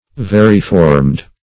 Search Result for " variformed" : The Collaborative International Dictionary of English v.0.48: Variformed \Va"ri*formed\, a. Formed with different shapes; having various forms; variform.